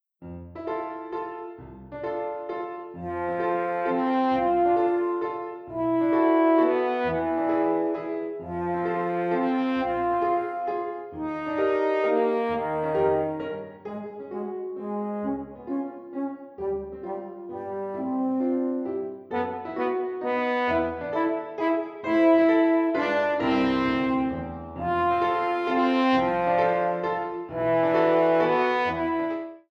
with Piano or backing tracks
Horn in F or Eb and Piano